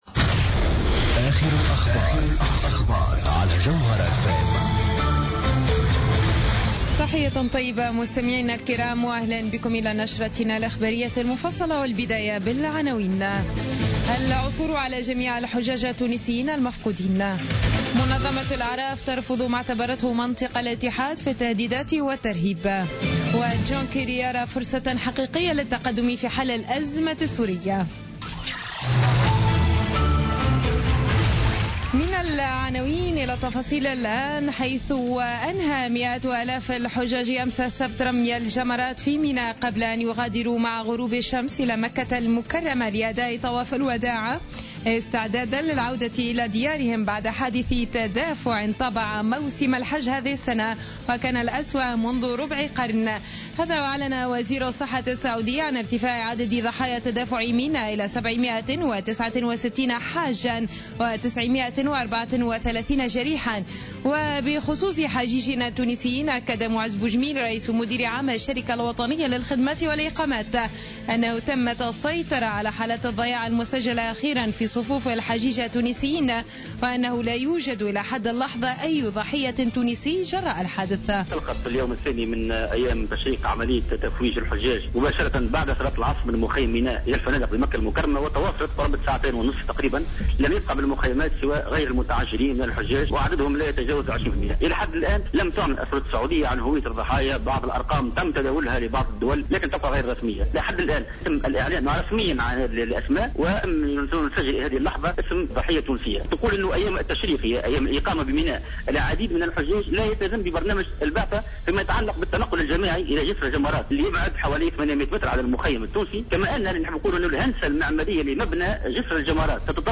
نشرة أخبار منتصف الليل ليوم الأحد 27 سبتمبر 2015